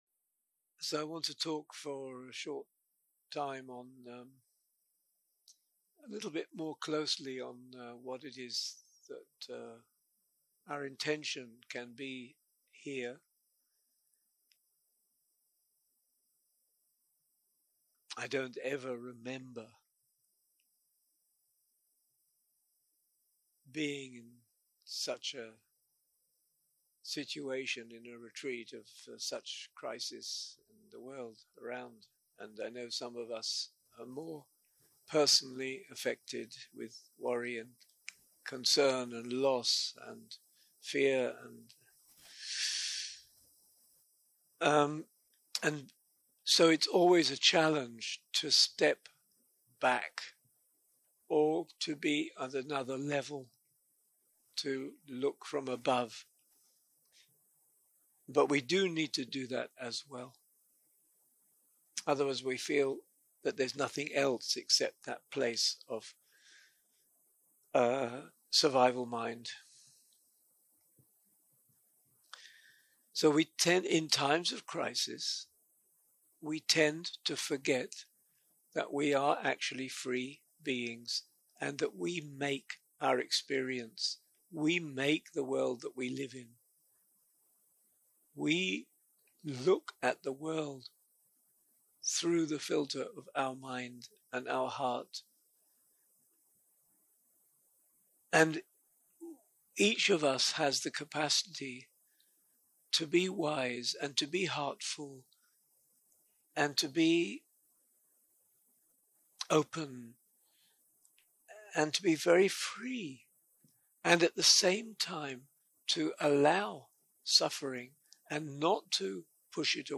יום 1 - הקלטה 1 - ערב - מדיטציה מונחית - The Eye of the Storm and the Storm
סוג ההקלטה: מדיטציה מונחית